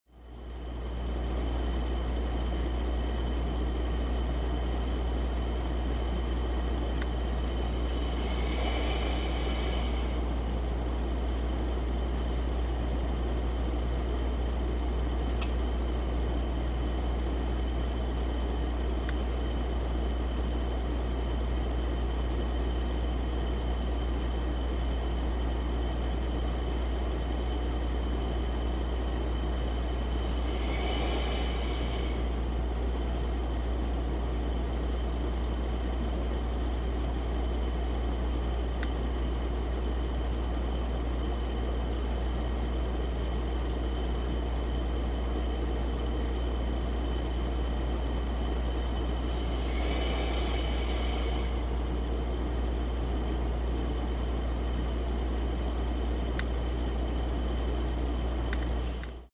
ダイニチ石油ファンヒーターの燃焼音
一番うるさくなると思われる、正面の騒音は、大火力だと35dBで、小火力だと24dBです。